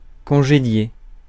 Prononciation
IPA: /kɔ̃.ʒe.dje/